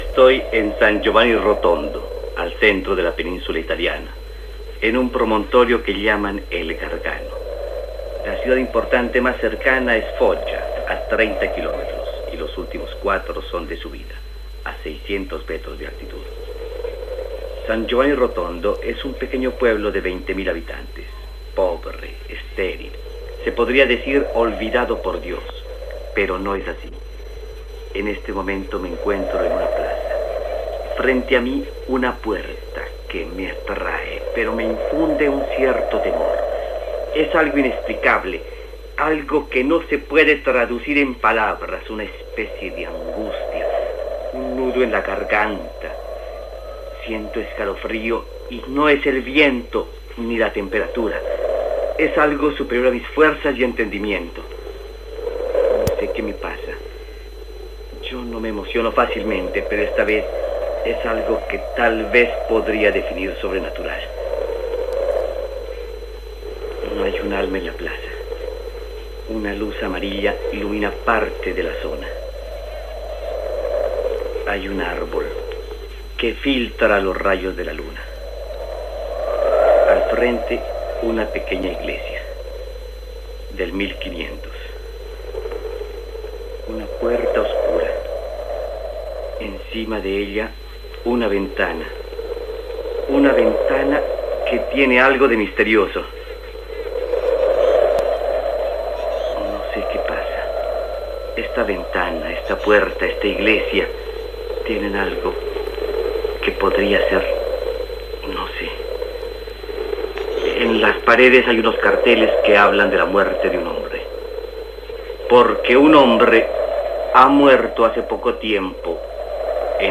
Descripció, des de San Giovanni Rotondo, un municipi italià, situat a la regió de la Pulla, de l'amnient que hi ha davant d'un convent